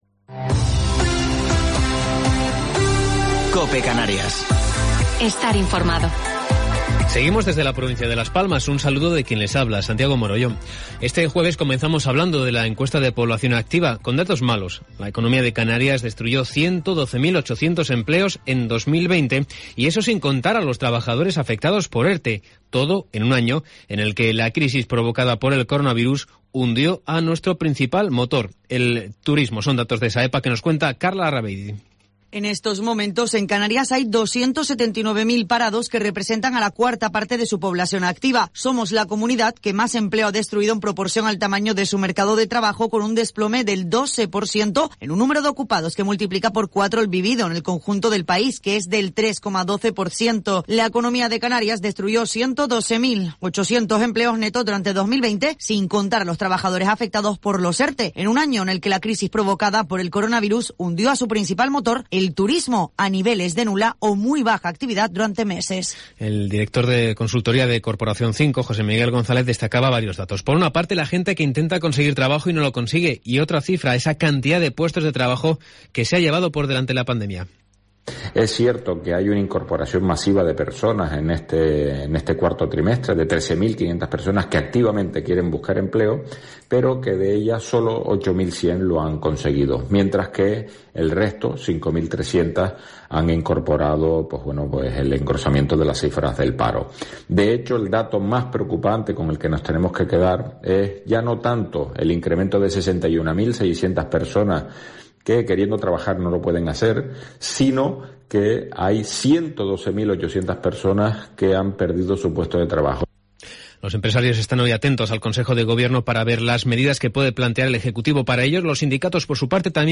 Informativo local 28 de Enero del 2021